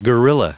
Prononciation du mot gorilla en anglais (fichier audio)
Prononciation du mot : gorilla